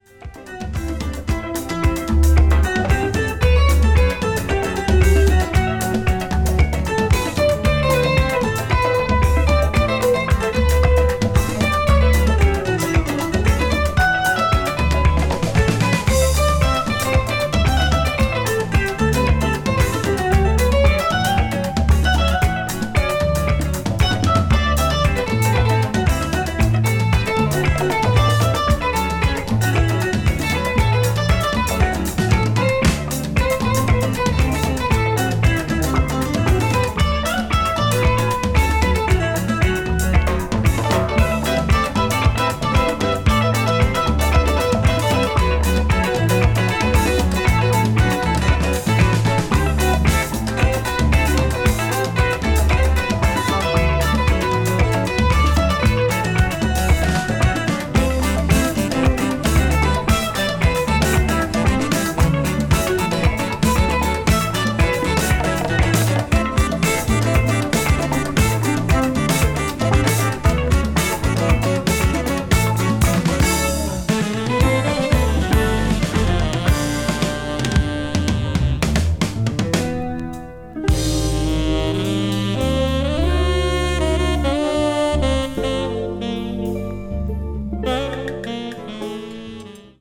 a lively Latin crossover cover